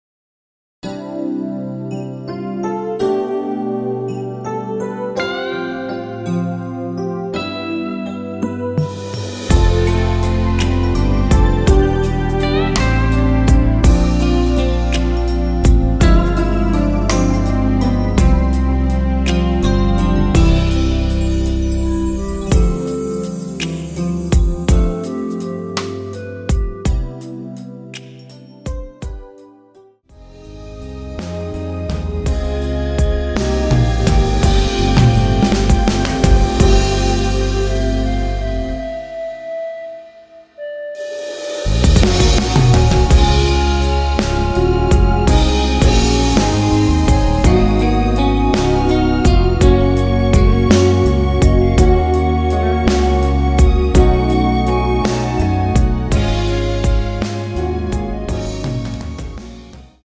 원키에서(-2)내린 멜로디 포함된 MR입니다.(미리듣기 참조)
멜로디 MR이라고 합니다.
앞부분30초, 뒷부분30초씩 편집해서 올려 드리고 있습니다.
중간에 음이 끈어지고 다시 나오는 이유는